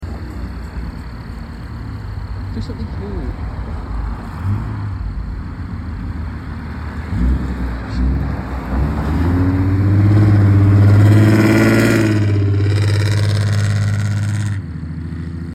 1992 (5th gen) Honda civic sound effects free download
Honda civic Mp3 Sound Effect 1992 (5th gen) Honda civic EG hatchback leaving car meet. Followed by a built up V6 Mustang.